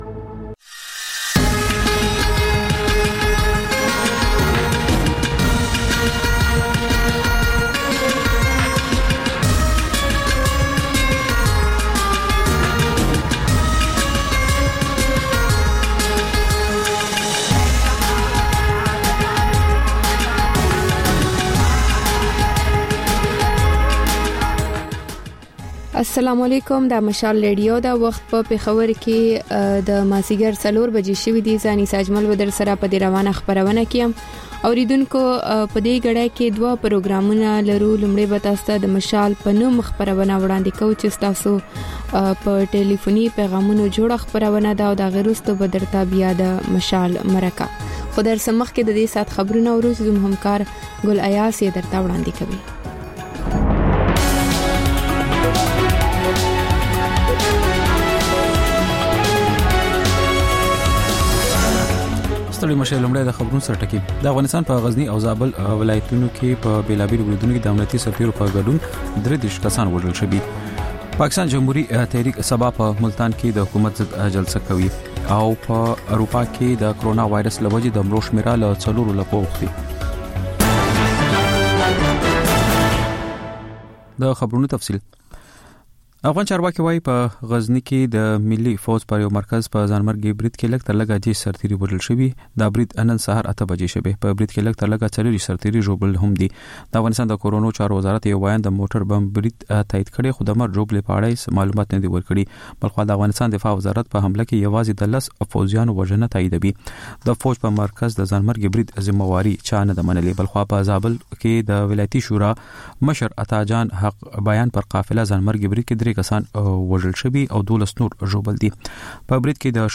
د خپرونې پیل له خبرونو کېږي، بیا ورپسې رپورټونه خپرېږي.